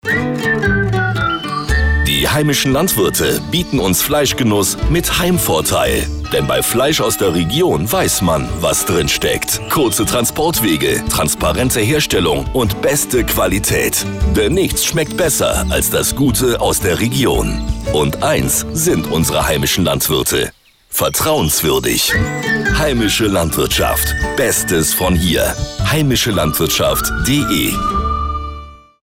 Radiospots zum Herunterladen